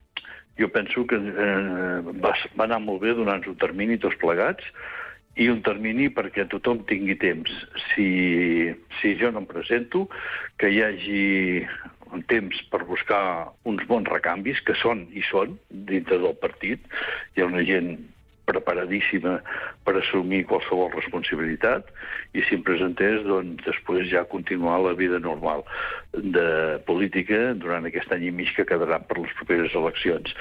En una entrevista al programa Supermatí de Ràdio Capital i Ràdio Palafrugell, l’alcalde de Torroella de Montgrí i l’Estartit, Jordi Colomí, ha fet un balanç de la situació actual del municipi.